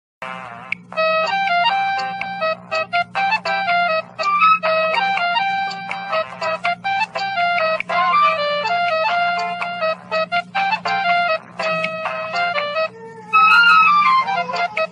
Arabic Nokia Ring